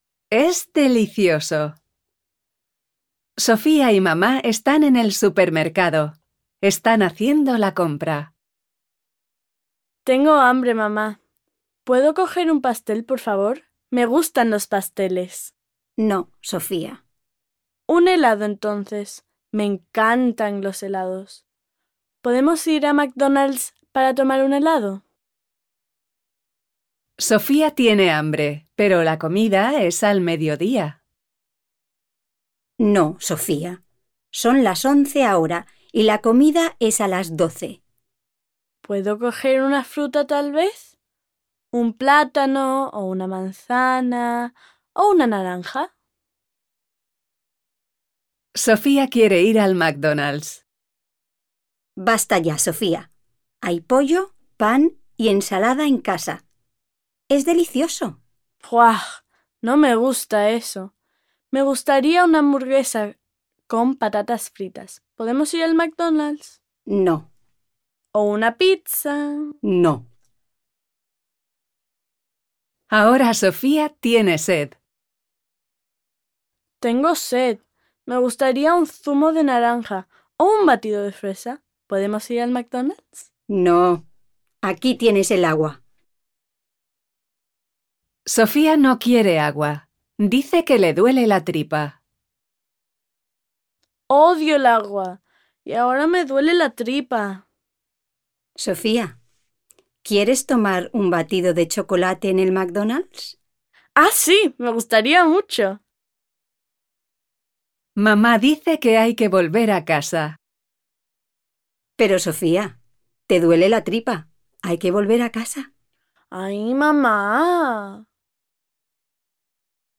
Listen to the story 'Es delicioso' performed by Spanish speakers